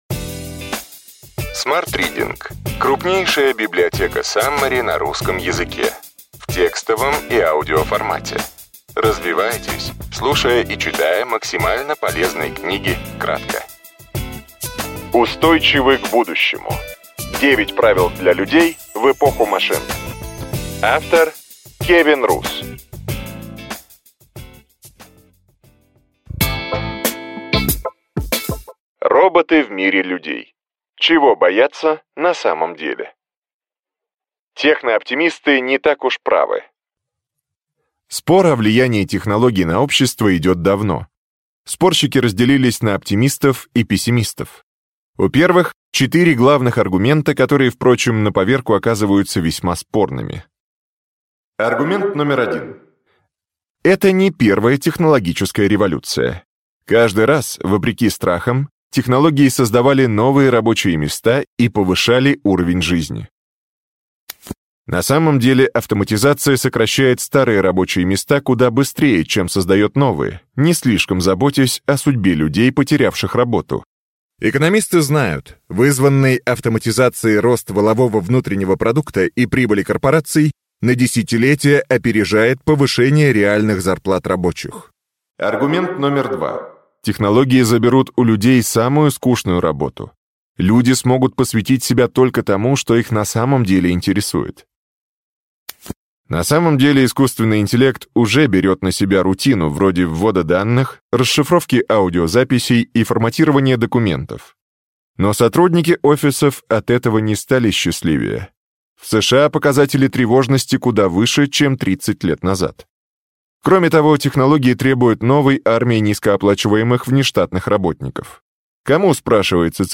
Аудиокнига Ключевые идеи книги: Устойчивы к будущему. 9 правил для людей в эпоху машин.